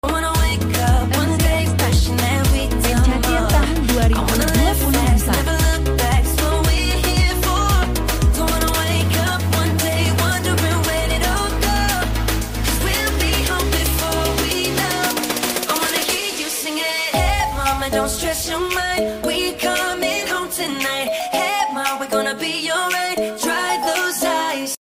Mc On Stage!!!